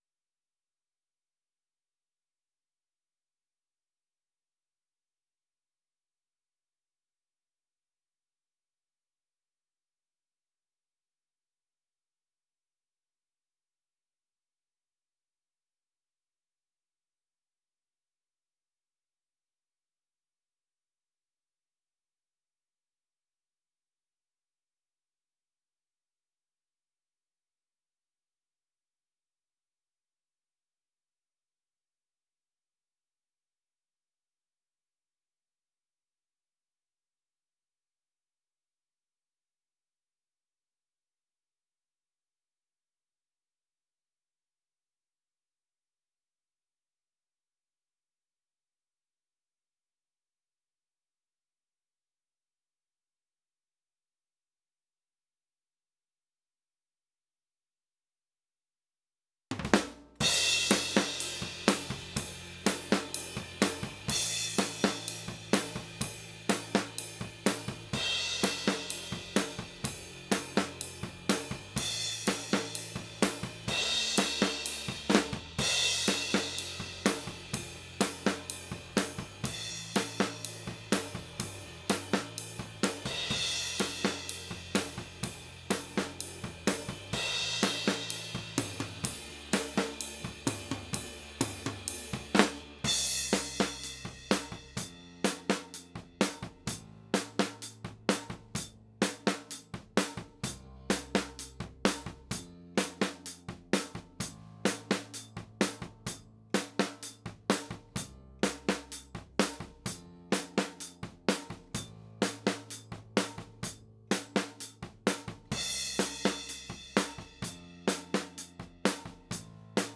White OHTom.wav